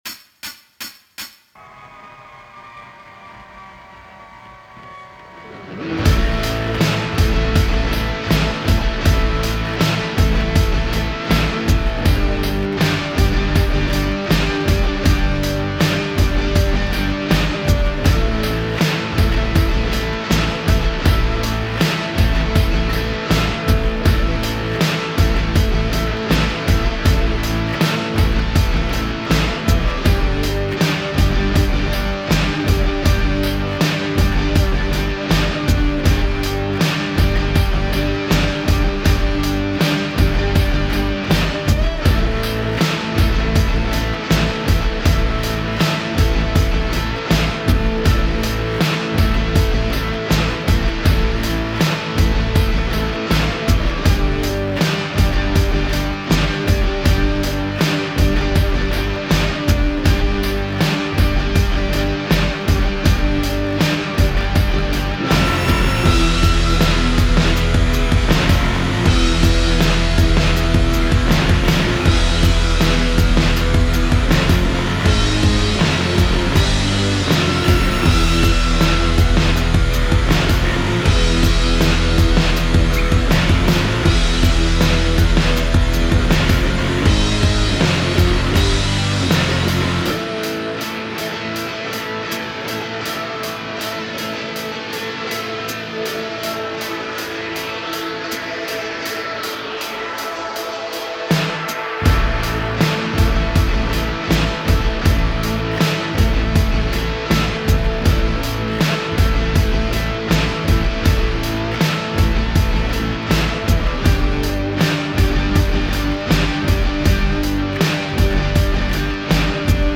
"Fusion Beat II" - Redneck Country Southern Gothic Beat (oder so)